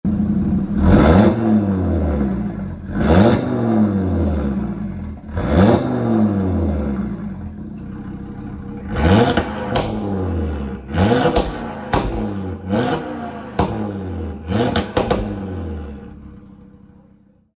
• DAZA Engine (Non-OPF): More tuneable than post-2019 Audi TTRS’s
Listen to this brute!
• ABT Catback Quad-Exit Sports Exhaust System: Black tips
ABT-TTRS-revs.mp3